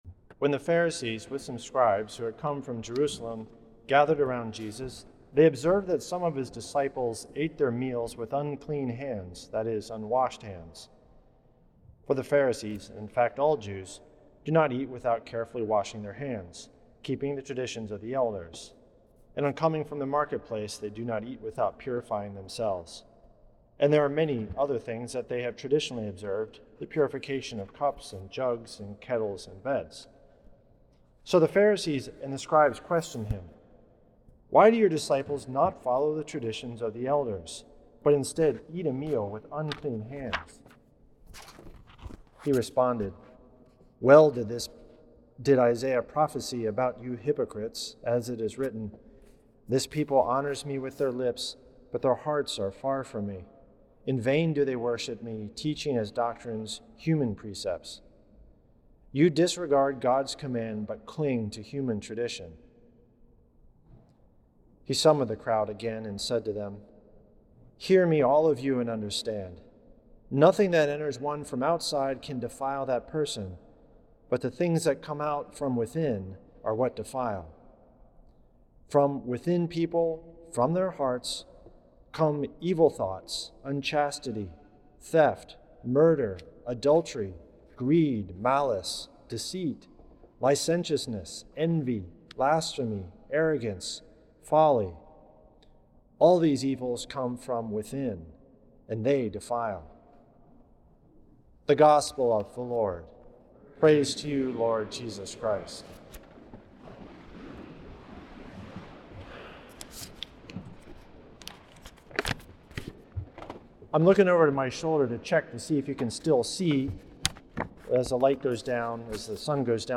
Homily
at St. Patrick’s Old Cathedral in NYC.